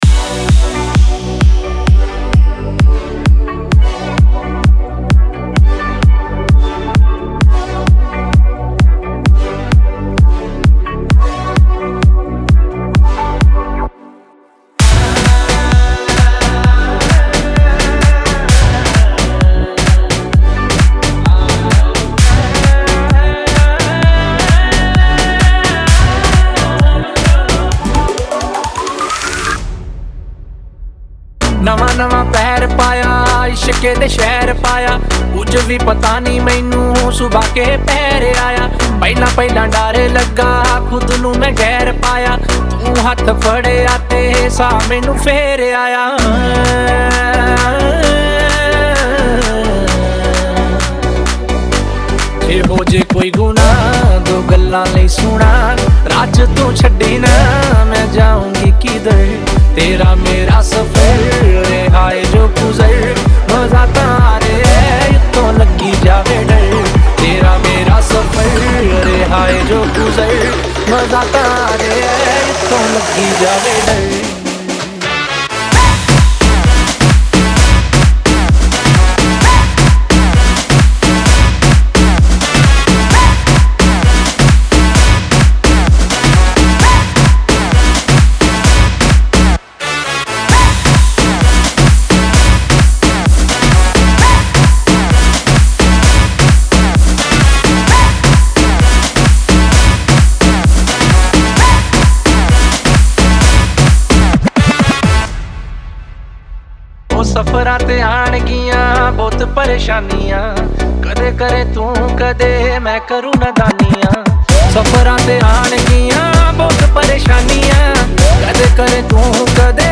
Club Mix